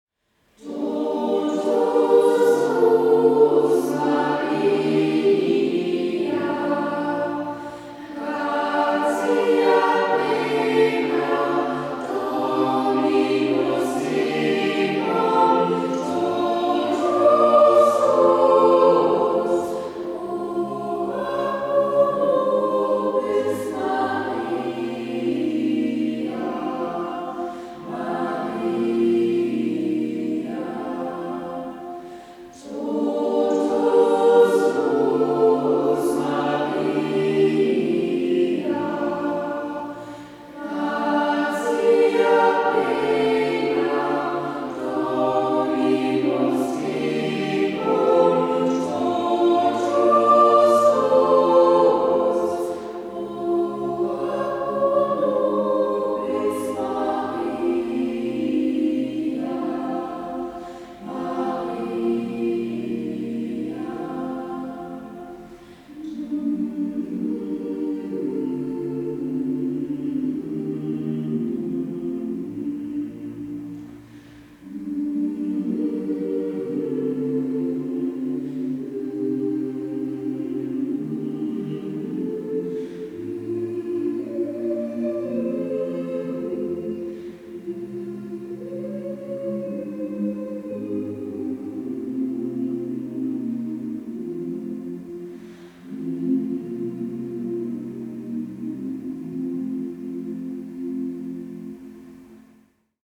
'Totus Tuus' gesungen von Mitgliedern des Kinderchores und der Liederwerkstatt.